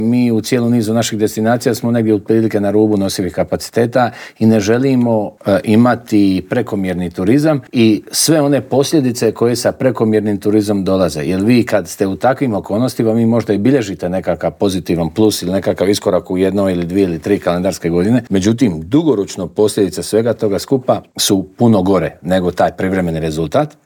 Inače, u prvih osam mjeseci došlo nam je 17,1 milijuna turista što je 2 posto više nego 2024. godine dok smo zabilježili i porast noćenja od 1 posto te ostvarili 89,9 milijuna noćenja o čemu smo u Intervjuu tjedna Media servisa razgovarali s ministrom turizma i sporta Tončijem Glavinom.